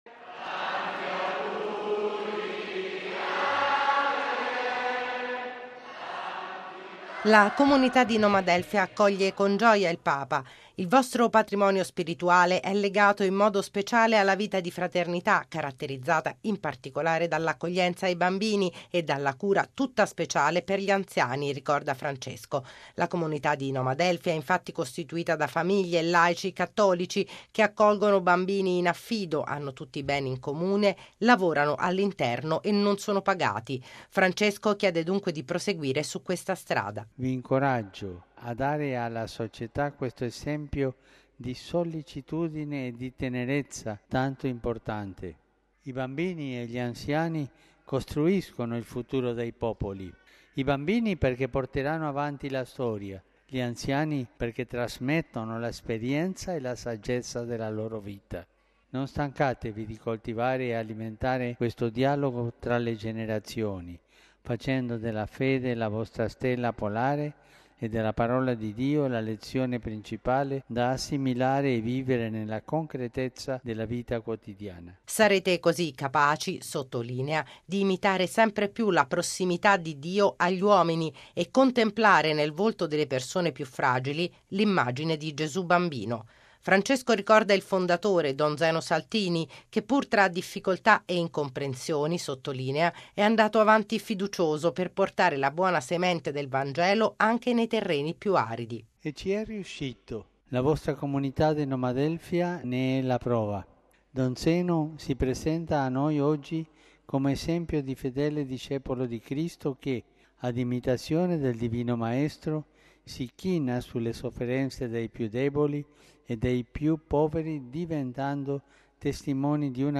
Date alla società l'importante esempio di sollecitudine e tenerezza in particolare verso bambini e anziani. Così il Papa nel suo discorso ai circa 330 membri della Comunità Nomadelfia, ricevuti stamani in Sala Clementina, in Vaticano. Francesco richiama l'esperienza del fondatore, don Zeno Saltini, e sottolinea che chi accoglie i deboli, ama Cristo.